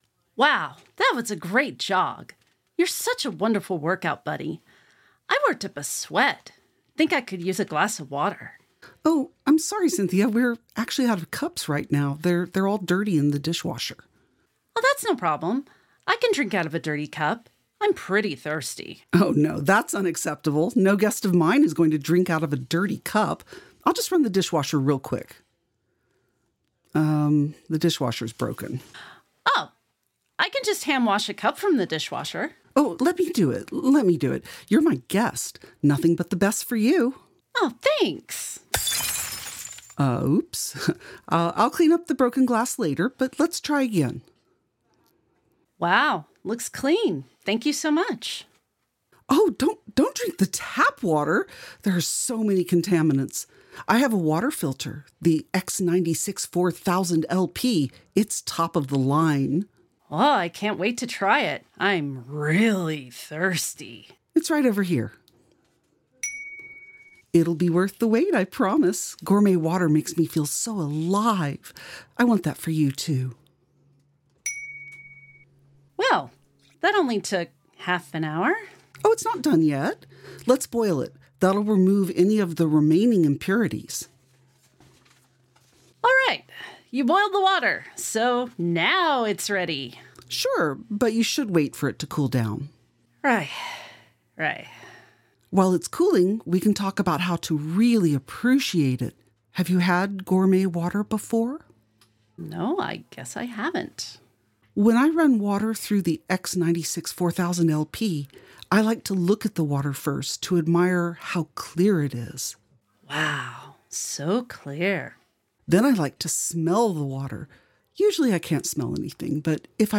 Format: Audio Drama
Voices: Solo
Genres: Comedy